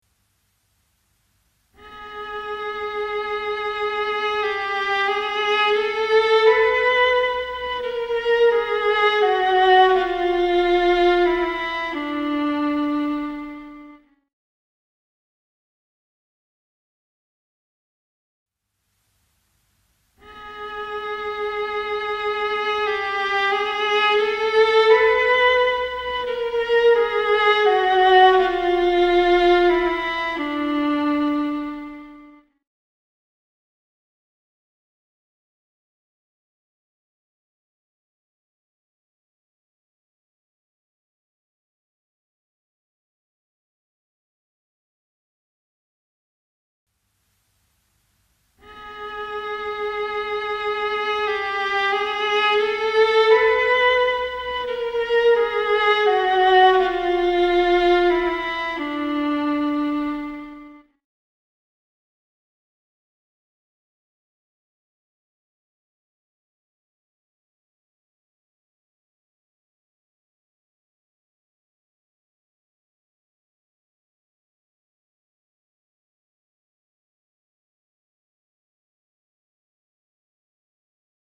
Du får höra varje exempel tre gånger efter varandra med allt längre tid emellan.